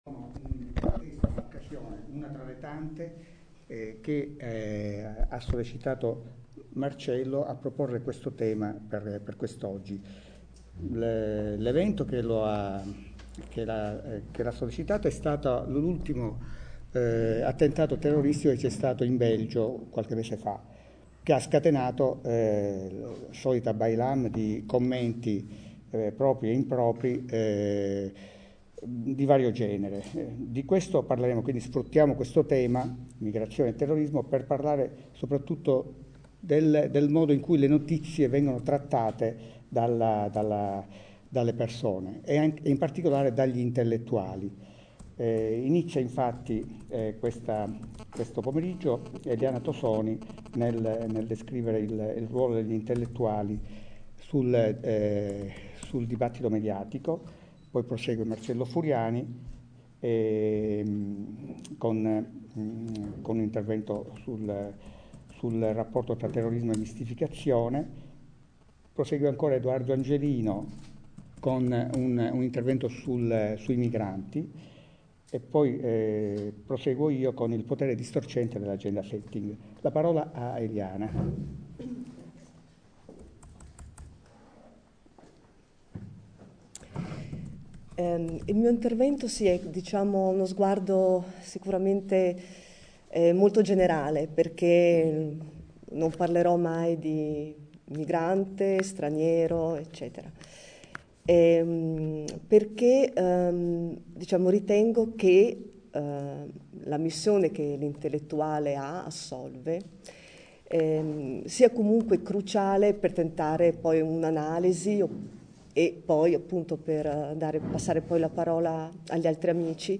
Lo scorso Sabato 2 Luglio, il Museo Arti e Mestieri di un Tempo di Cisterna ha ospitato ilseminario filosofico, in collaborazione con il gruppo culturale Oron Oronta e il Polo Cittattiva per l’Astigiano e l’Albese, sul rapporto tra informazione e dibattito culturale: MIGRAZIONI, TERRORISMO E POPULISMO MEDIATICO. Gli interventi, che si sono avvicendati nel pomeriggio, hanno tracciato una linea comune partendo dal ruolo dell’intellettuale di oggi, passando per l’impoverimento del pensiero alla base di certe spettacolarizzazioni o semplificazioni dei fenomeni in oggetto, lasciando sul tavolo della discussione la distinzione fra ospitalità ed accoglienza, la veridicità delle fonti e la credibilità dell’informazione.